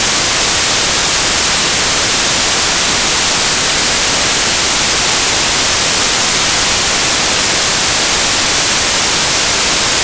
5. The cable will automatically complete the upgrade in 50s, and no action is required. There would be noise generated on the upgrade page, please ignore it.